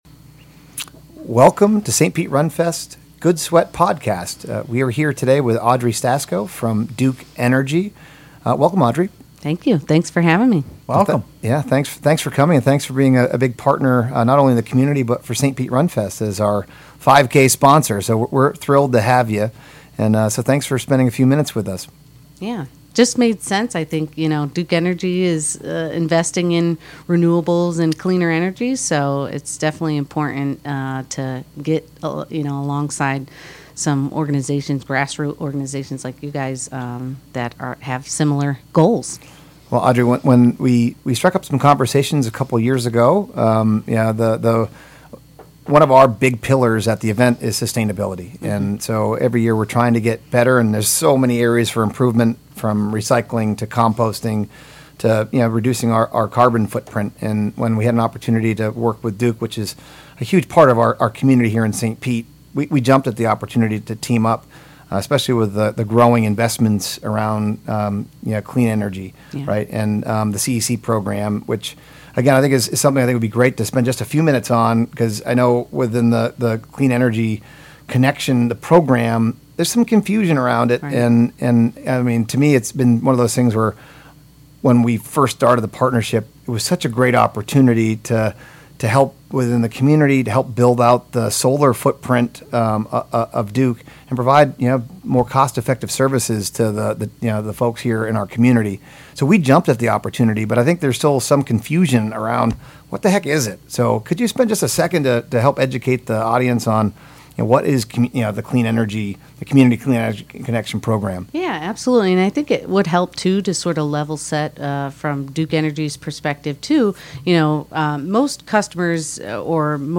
in the RadioStPete studio